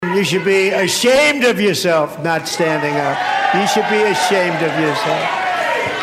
Trump talks about illegal immigration as Representatives Ilhan Omar from Minnesota and Rashida Tlaib of Michigan shouted at him.